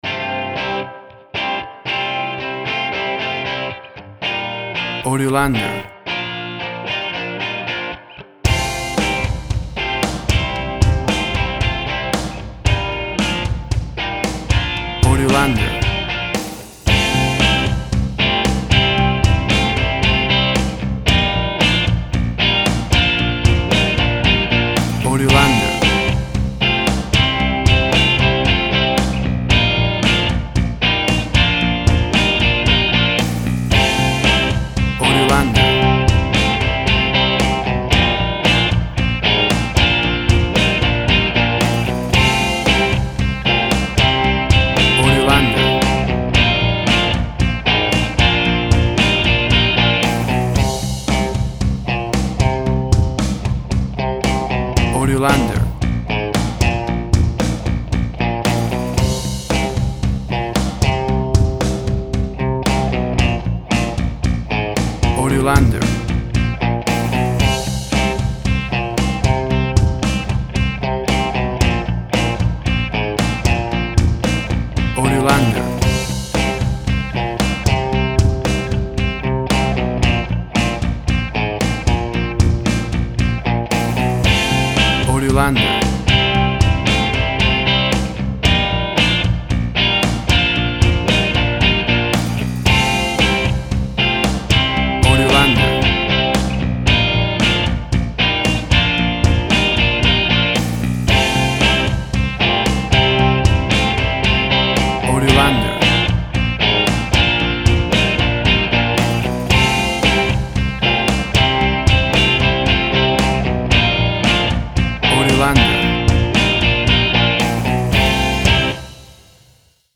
British retro rock from the 60’s, similar to Rolling stones
Tempo (BPM) 115